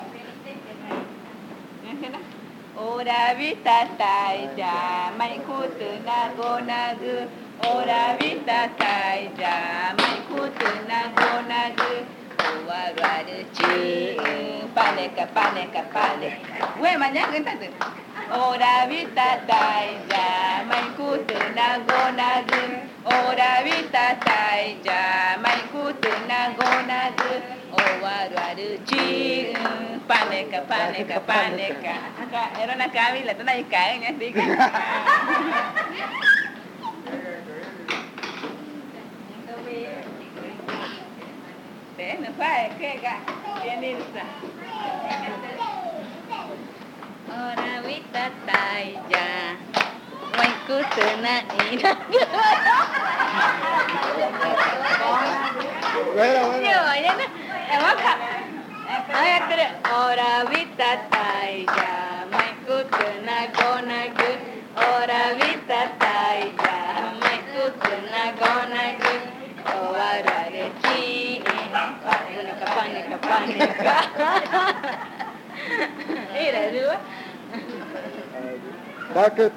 Cantos 4. Cantos y juegos de niños
HC-BOYAHUASU-CAS001-A-004-canto.juego.mp3 (1.29 MB)
Canción de juego tradicional magütá
en el marco del Acto Cultural en la Comunidad Indígena de Boyahuasú (Amazonas, Colombia). La canción fue grabada en casete el 19 de enero del año 2002.
Song of traditional magütá game
The song was recorded on cassette on January 19, 2002.